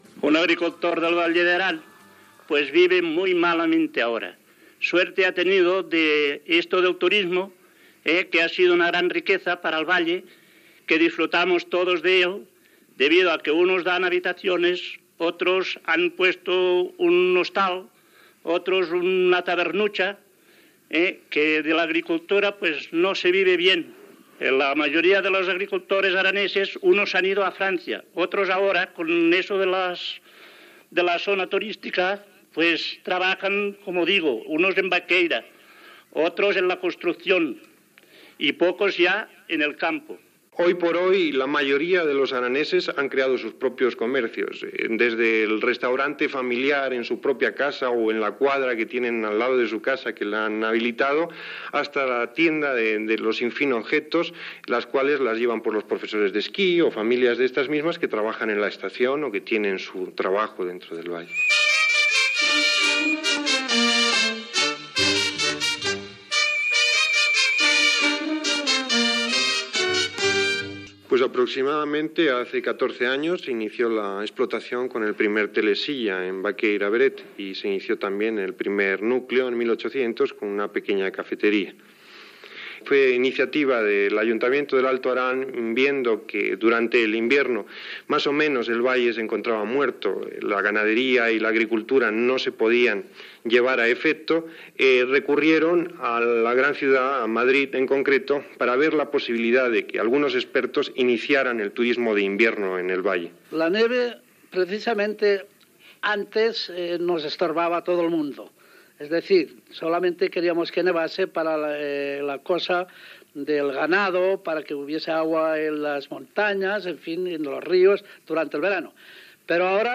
Declaracions de dos pagesos de La Vall d'Aran i record de com es va posar en marxa l'estació d'esquí de Baqueira Beret
Informatiu